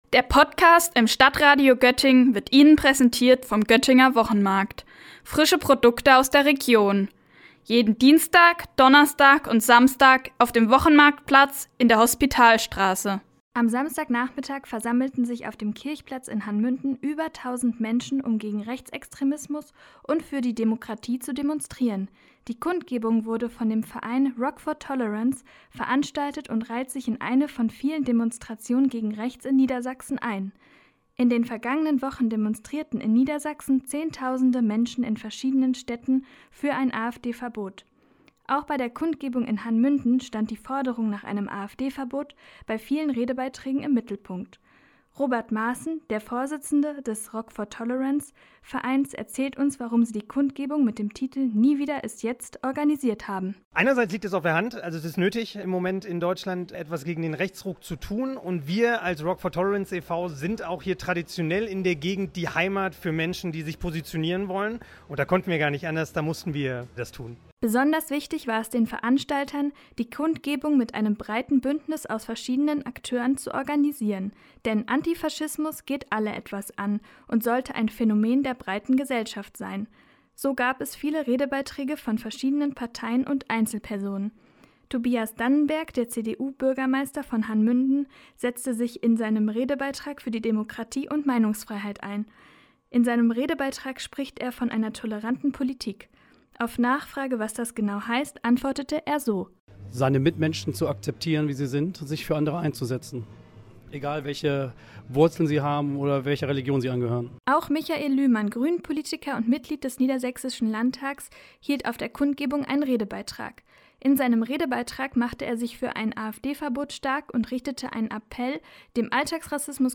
Am vergangenen Samstag hat ein breites Bündnis bestehend aus Vereinen, Parteien und Einzelpersonen in Hann. Münden zu einer Kundgebung gegen Rechts aufgerufen. Auf dem Kirchplatz demonstrierten unter dem Motto „Nie wieder ist jetzt“ über 1.000 Menschen auf dem gegen Rechts und für Menschenrechte.